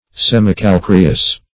Search Result for " semicalcareous" : The Collaborative International Dictionary of English v.0.48: Semicalcareous \Sem`i*cal*ca"re*ous\, a. Half or partially calcareous; as, a semicalcareous plant.